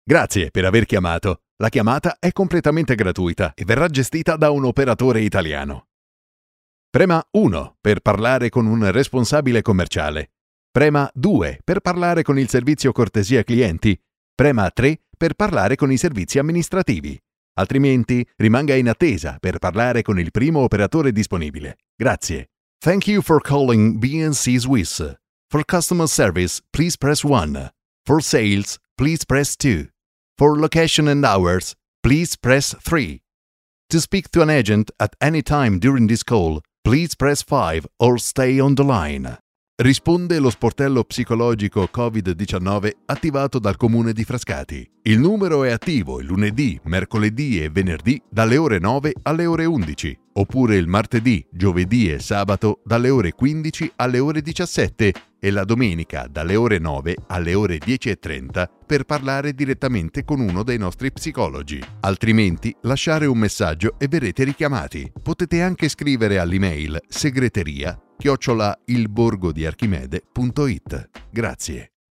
IVR
My voice is deep, mature, warm and enveloping, but also aggressive, emotional and relaxing.